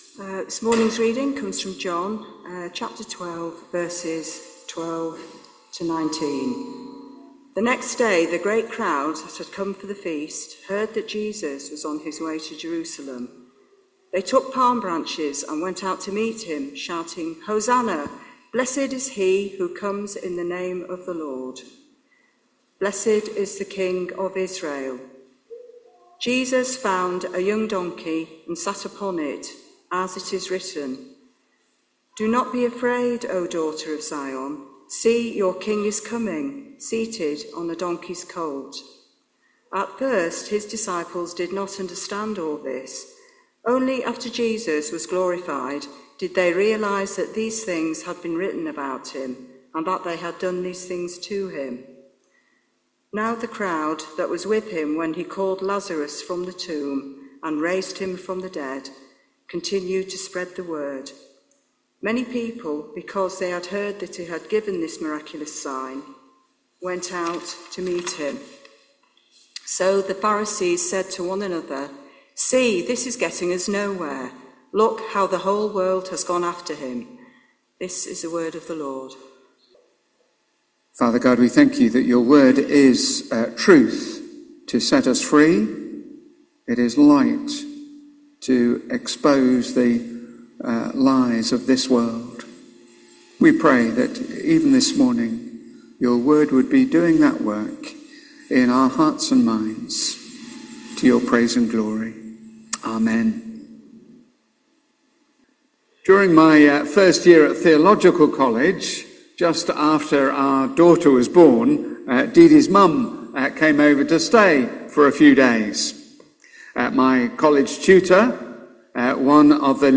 Talk starts with prayer at 1.20